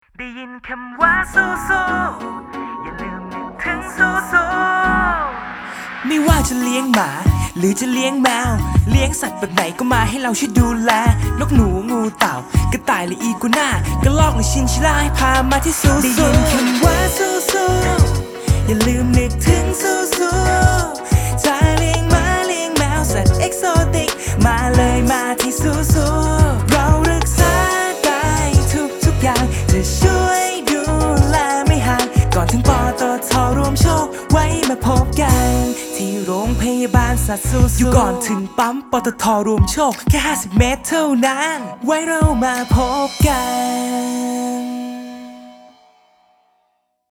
( รูปแบบท่อน VERSE + RAP + HOOK )